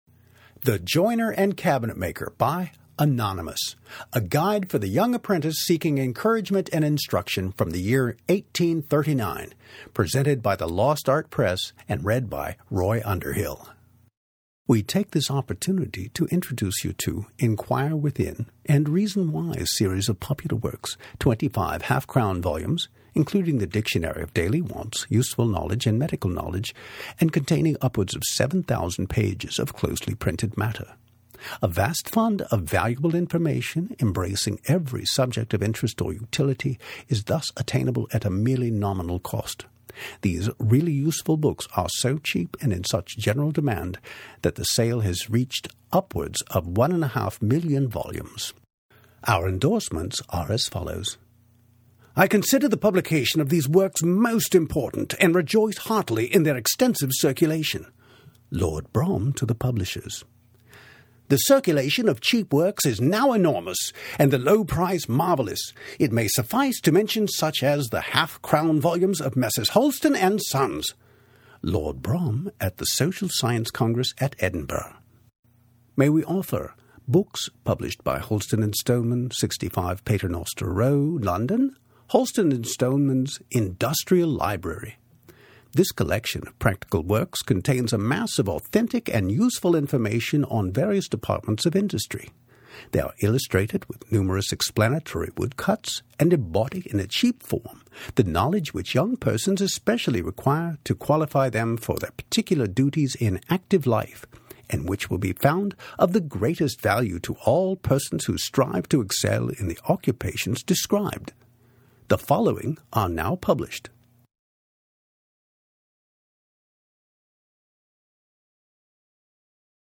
In the meantime, listen to this short “commercial” by Underhill where he reads some of the promotional copy for the original book (by the way, this was mastered by me – the audiobook sounds much cleaner).
The promo cuts out after 2 minutes.